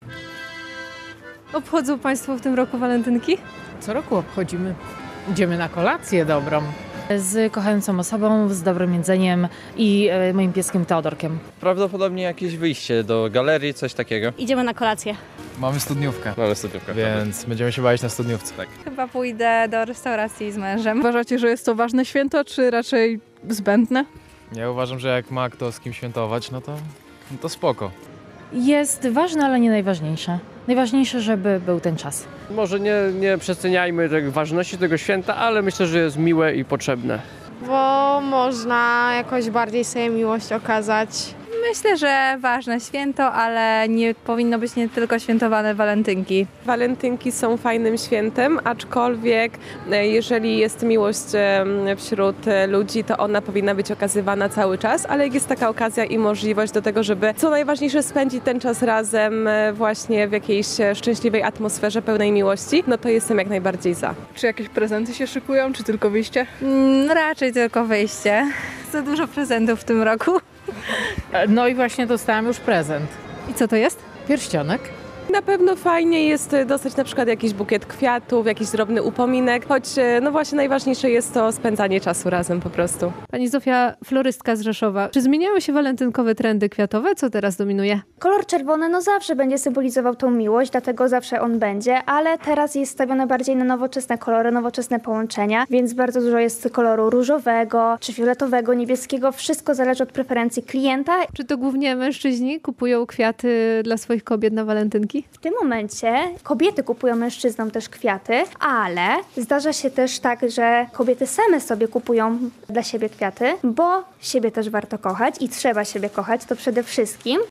Zapytaliśmy mieszkańców Podkarpacia, jak świętują walentynki.
O walentynkowe trendy zapytaliśmy również florystkę.